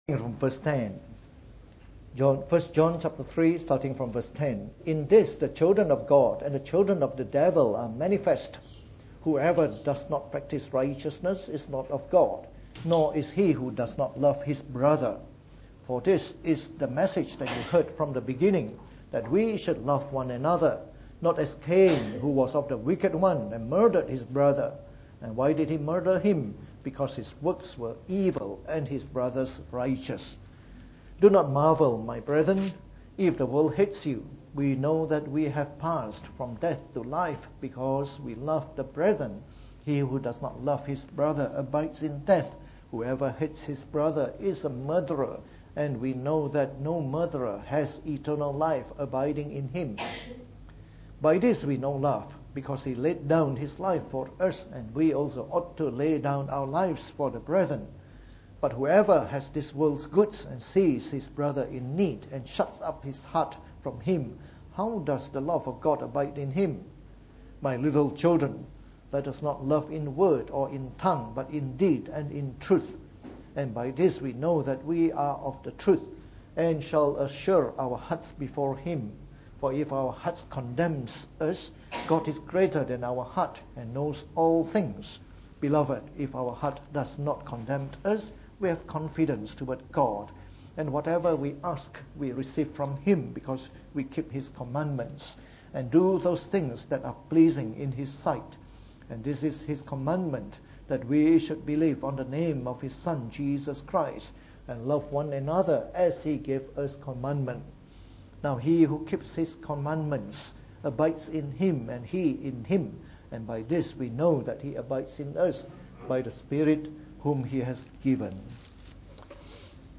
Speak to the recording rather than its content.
From our series on the Book of 1 John delivered in the Morning Service.